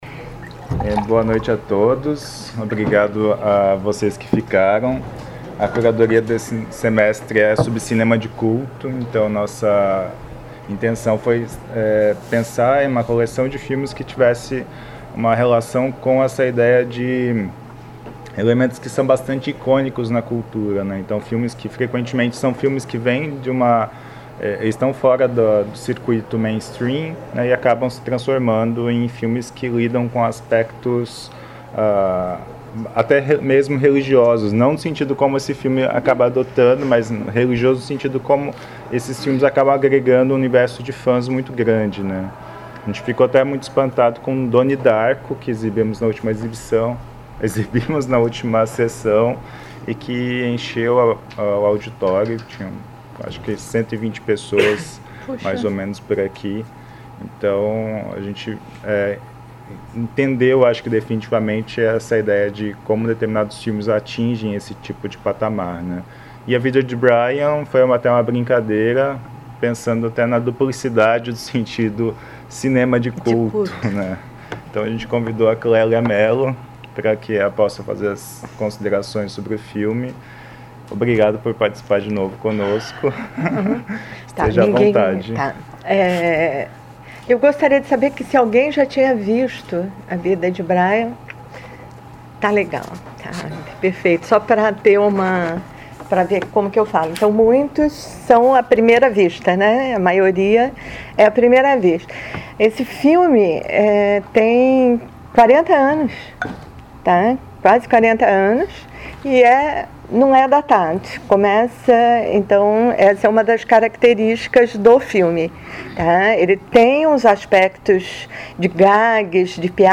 na sessão de exibição e debate do filme "A Vida de Brian" (Life of Brian, ano de produção: 1979), do diretor Terry Jones, realizada em 12 de maio de 2016 no Auditório "Elke Hering" da Biblioteca Central da UFSC.